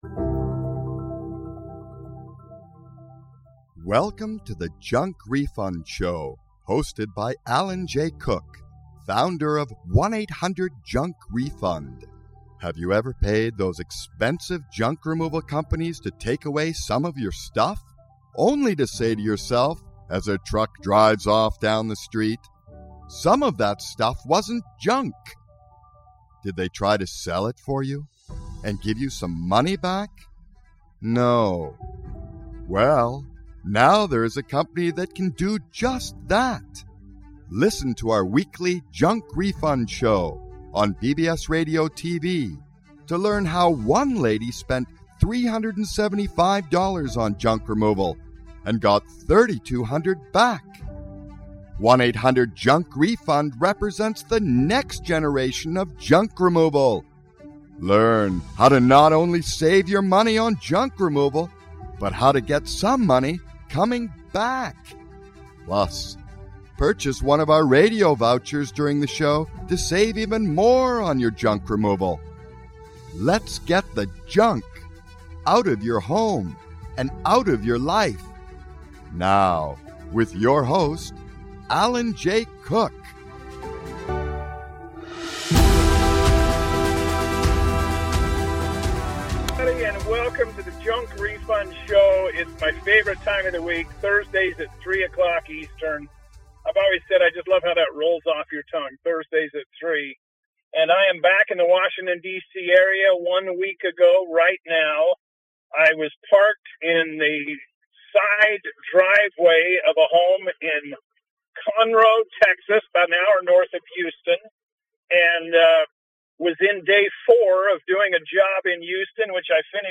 Enjoy this show broadcast from the freeway in Washington DC!